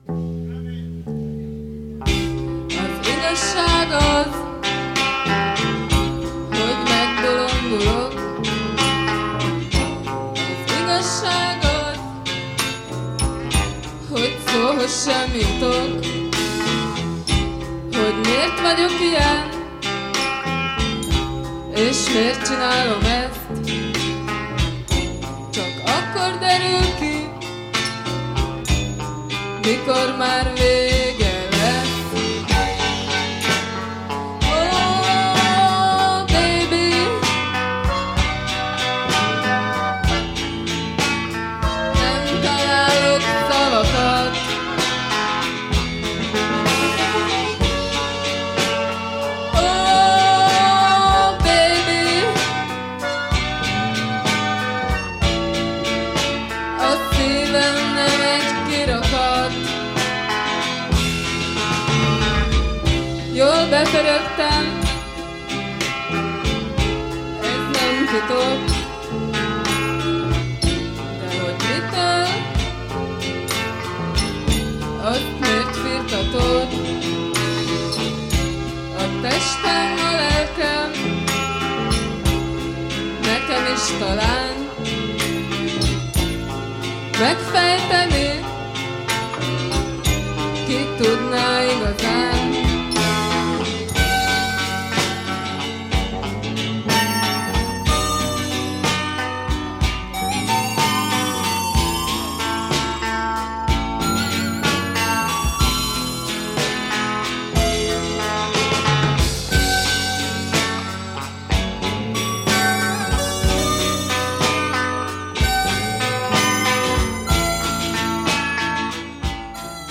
un groupe underground, d'avant-garde